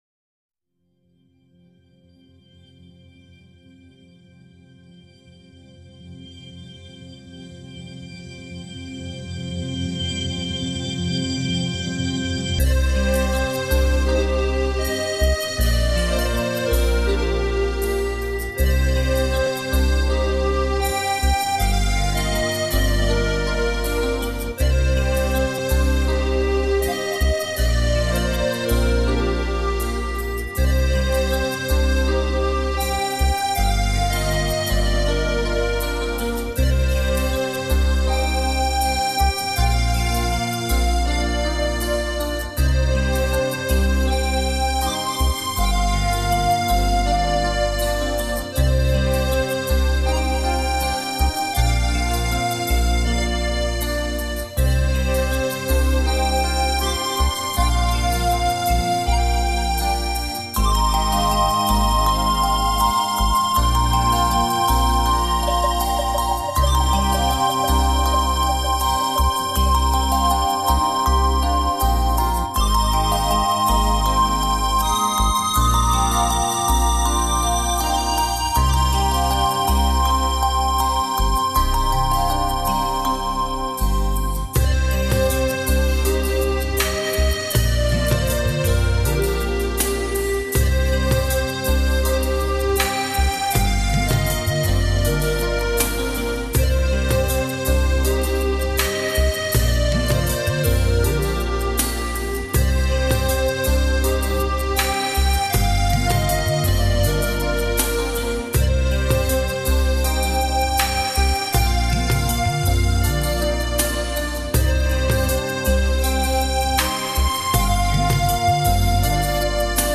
用电子合成设备来模仿民族音乐的魂